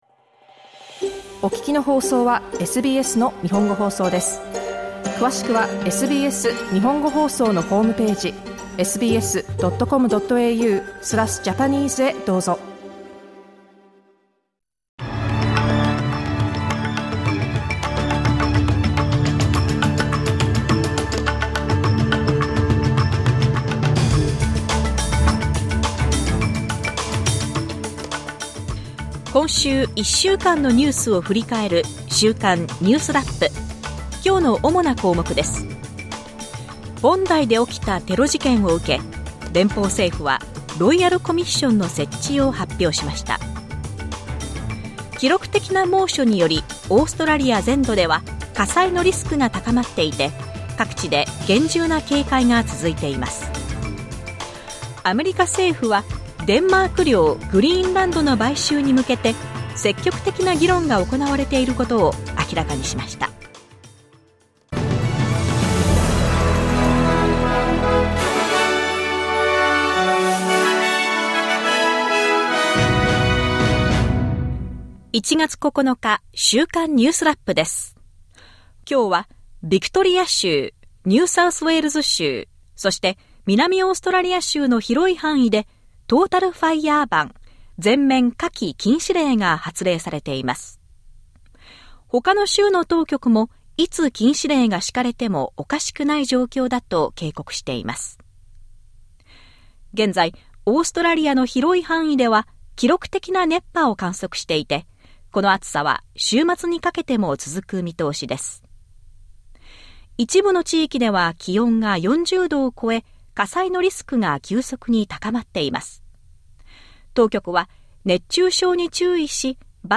SBS日本語放送週間ニュースラップ 1月9日金曜日